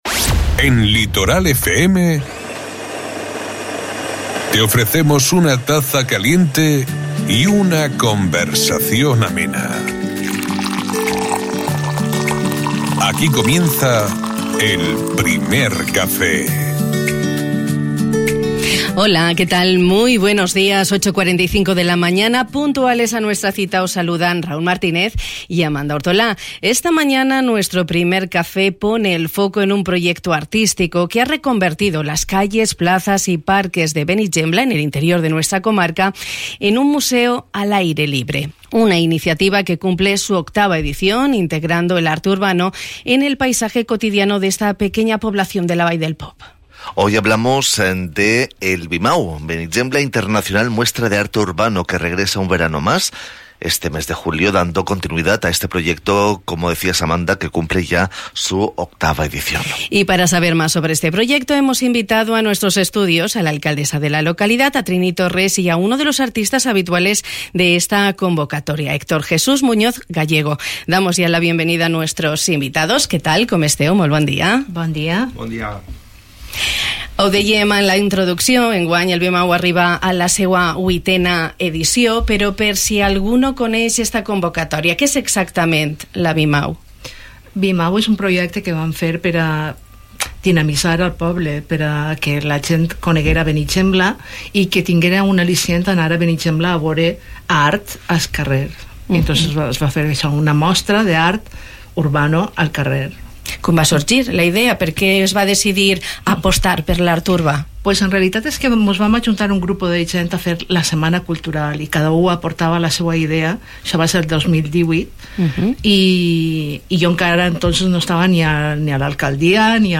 Nos lo han contado la alcaldesa de Benigembla, Trini Torres, y uno de los artistas habituales en esta convocatoria